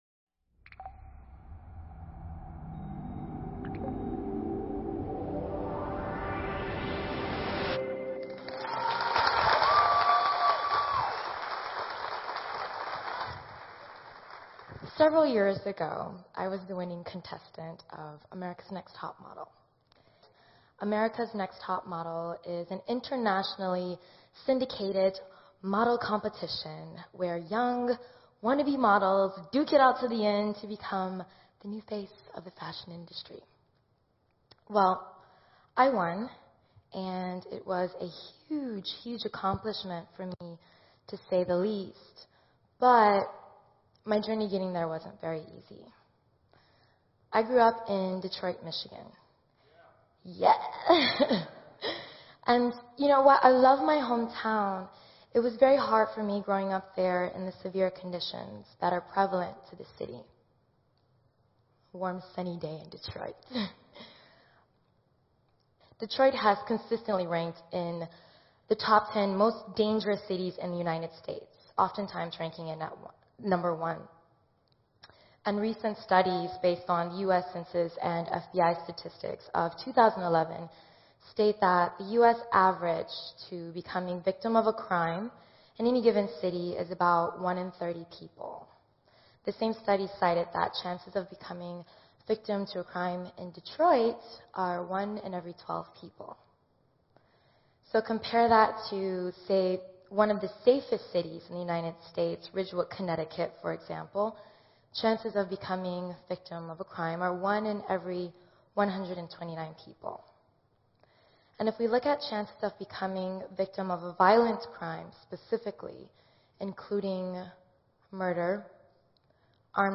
Full text of Naima Mora on Inspiration’s Potential to Change the World at TEDxSacramento conference.